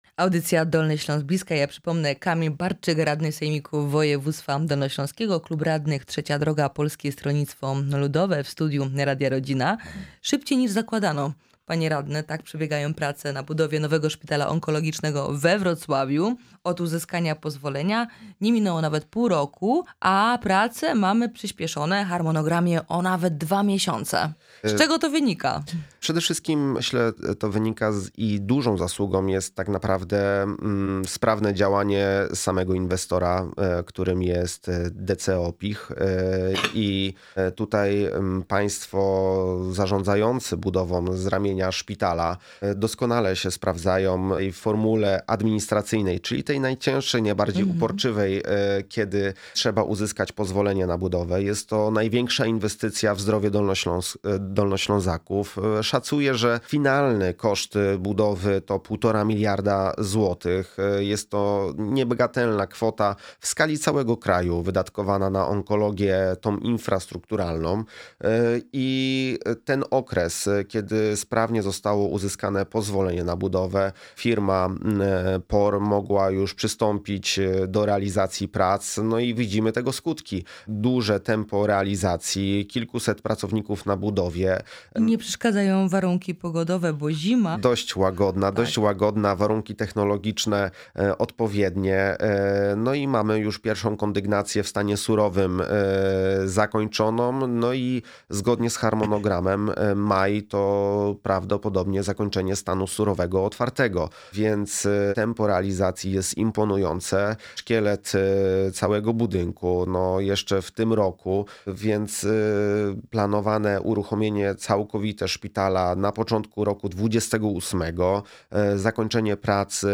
Radny Sejmiku Kamil Barczyk w audycji „Dolny Śląsk z bliska”
O usuwaniu skutków powodzi i przywracaniu infrastruktury po żywiole, który nawiedził nasz region we wrześniu ubiegłego roku, zaawansowaniu prac budowlanych szpitala onkologicznego oraz nowych połączeniach kolejowych rozmawiamy z naszym gościem. Jest nim Kamil Barczyk radny Sejmiku Województwa Dolnośląskiego (Klub Radnych: Trzecia Droga – Polskie Stronnictwo Ludowe).